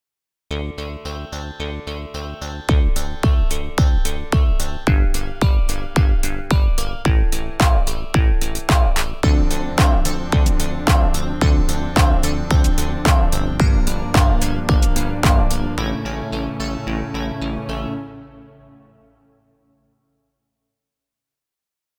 Hieronder zie je hoe ik een aantal eenvoudige akkoorden in D mineur heb ingevoerd in Band-in-a-Box.
Wat je hier hoort is de audio die Band-in-a-Box op basis van de gekozen akkoorden en stijl heeft gegenereerd.